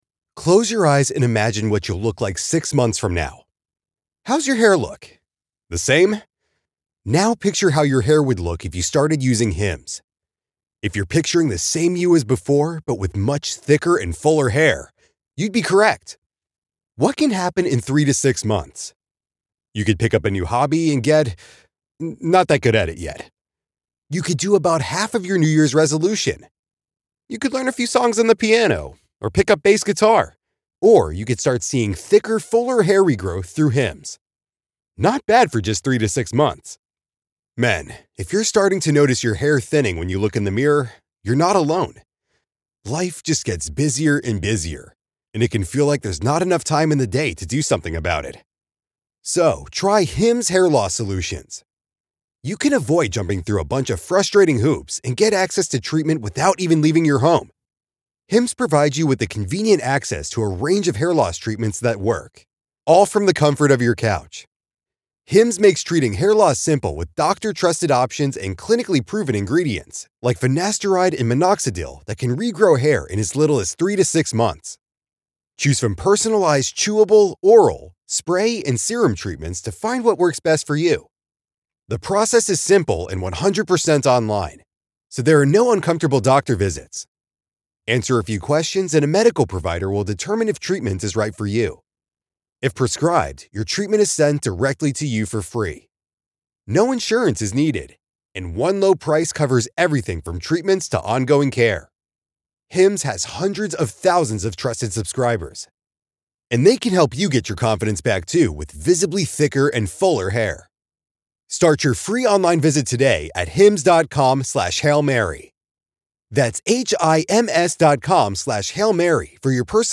Tony Khan speaks to media ahead of AEW Dynasty on Triller PPV on April 7.